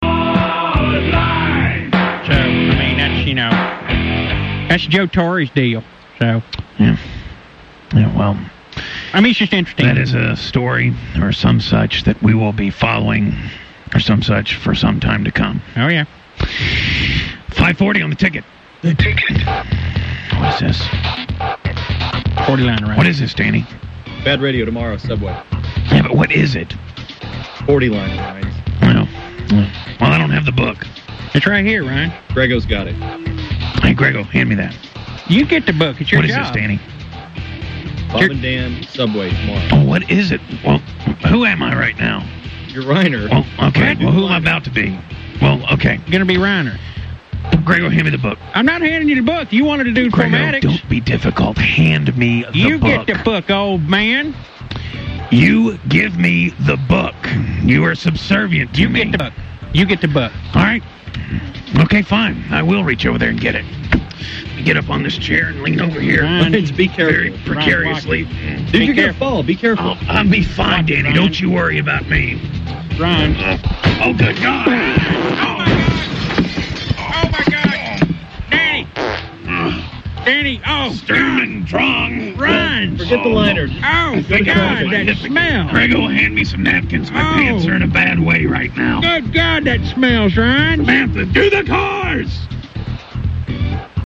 Problem was, he had to stand on a chair to get it and something went wrong. Sounded pretty nasty. http